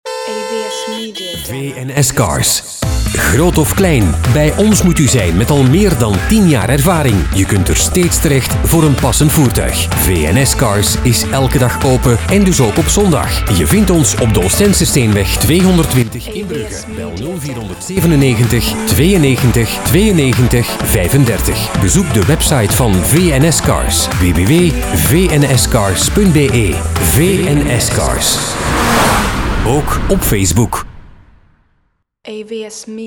vns-cars-brugge-radio-spot.mp3